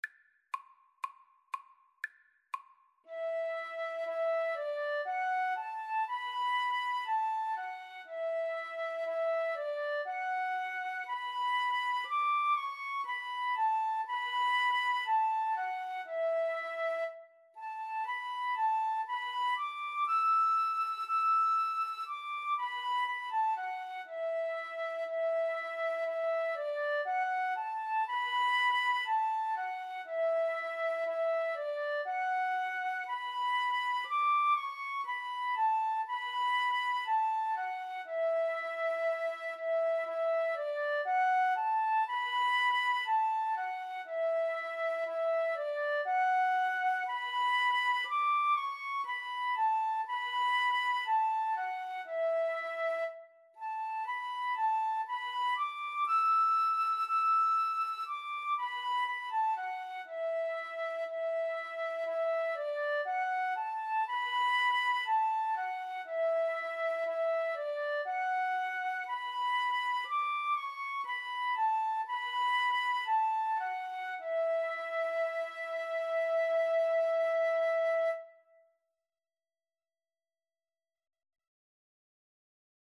Andante Cantabile = c. 60
Violin-Flute Duet  (View more Easy Violin-Flute Duet Music)
Classical (View more Classical Violin-Flute Duet Music)